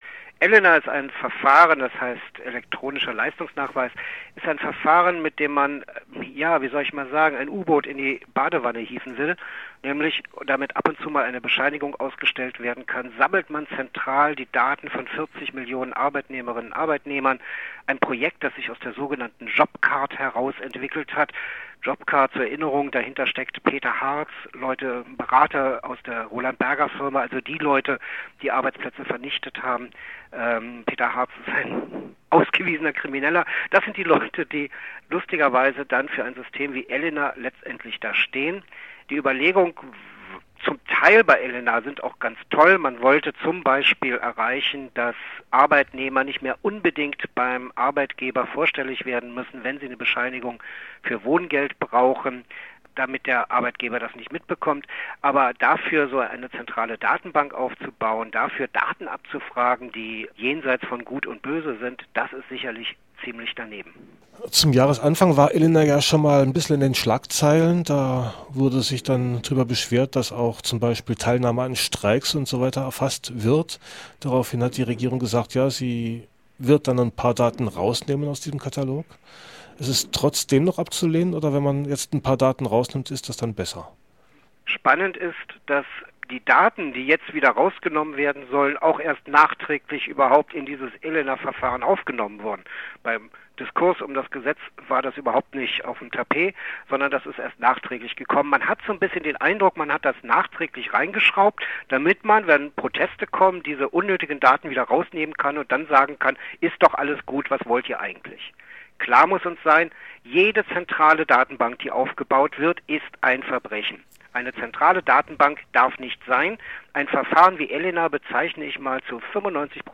Ein Interview